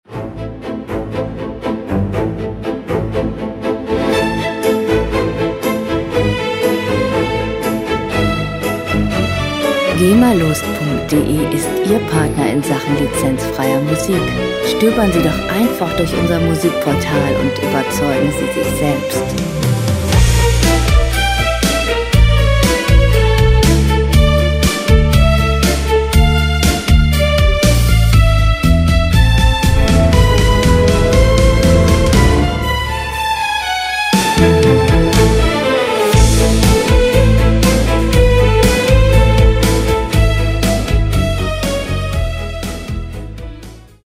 Klassik Pop
Musikstil: Orchestral Pop
Tempo: 120 bpm
Tonart: A-Moll
Charakter: rasant, geschmackvoll